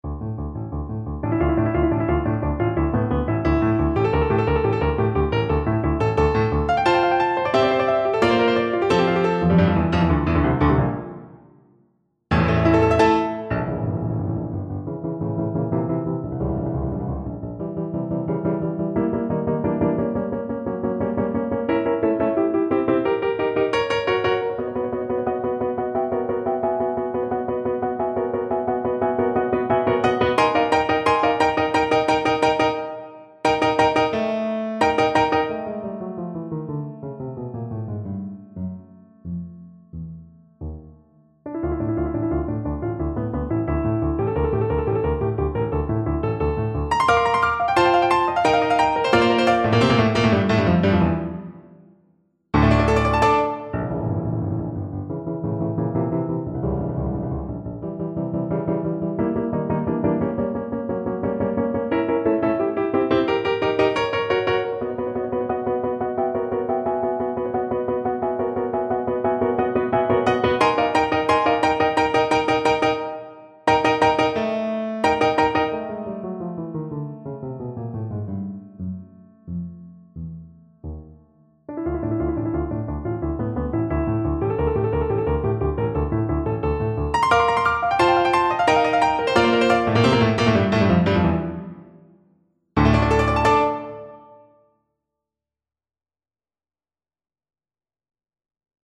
Play (or use space bar on your keyboard) Pause Music Playalong - Piano Accompaniment Playalong Band Accompaniment not yet available transpose reset tempo print settings full screen
Trombone
D minor (Sounding Pitch) (View more D minor Music for Trombone )
2/2 (View more 2/2 Music)
Allegro molto = 176 (View more music marked Allegro)
Classical (View more Classical Trombone Music)